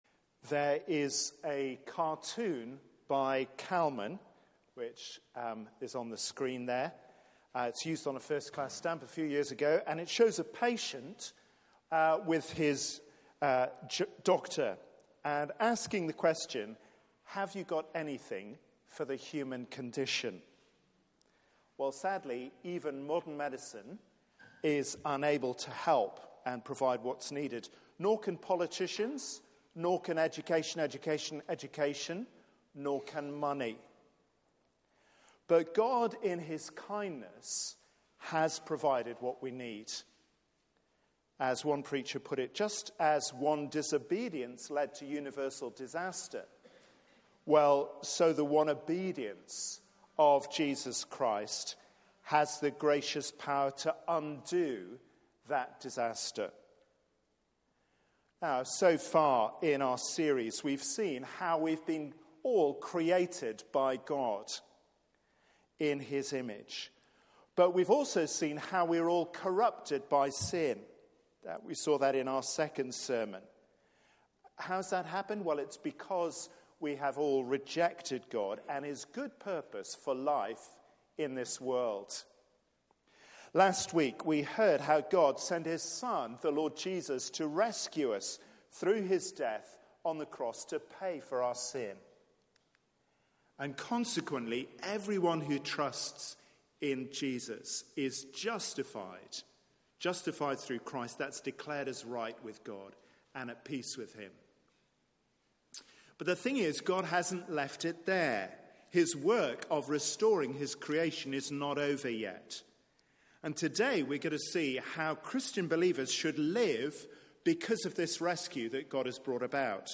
Media for 6:30pm Service on Sun 27th Jan 2019 18:30 Speaker
Series: Knowing who you are Theme: Raised with Christ Sermon